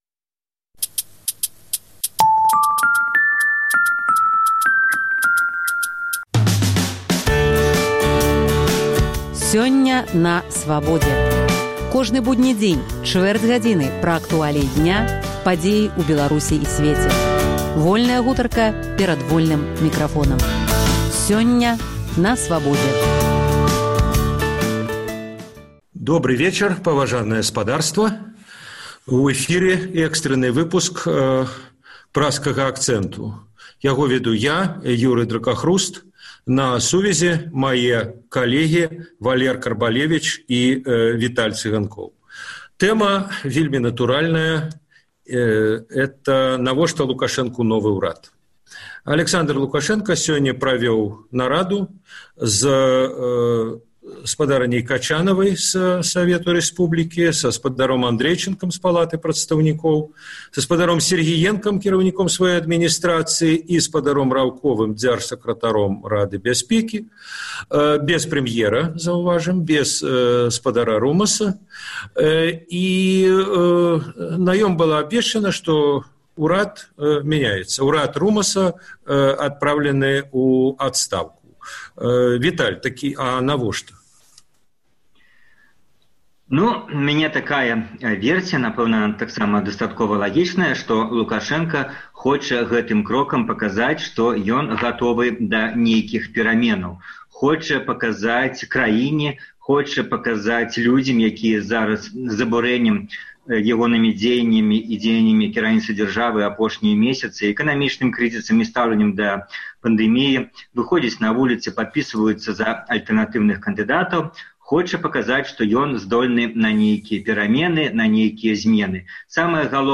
Гэтыя пытаньні ў Праскім акцэнце абмяркоўваюць палітычныя аналітыкі